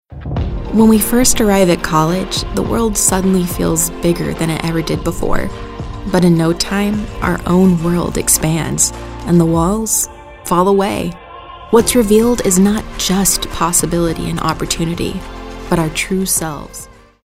anti-announcer, conversational, cool, friendly, genuine, girl-next-door, millennial, narrative, real, storyteller, thoughtful, young adult